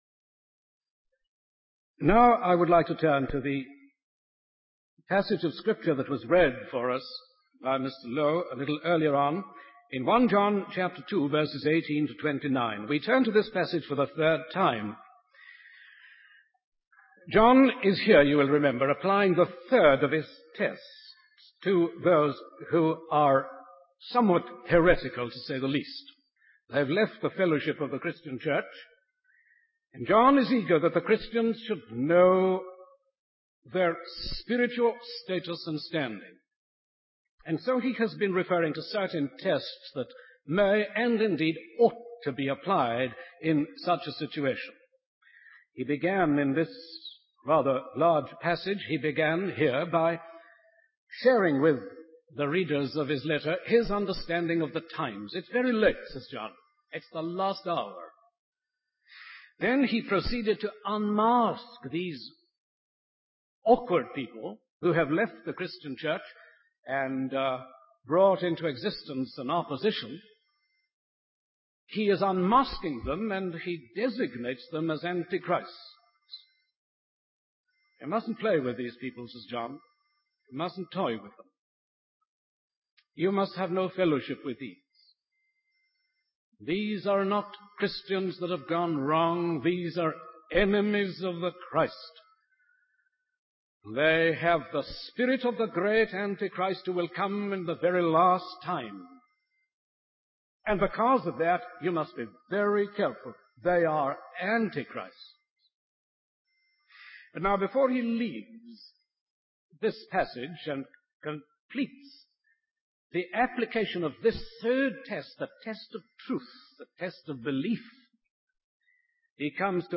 In this sermon, the speaker emphasizes the importance of holding onto the truth of God's word and not being swayed by false teachings or novel ideas. He encourages the early Christians to rely on the anointing of the Holy Spirit and the guidance of the word of truth. The speaker highlights the universality of this truth, particularly in times of distress, danger, and heresy.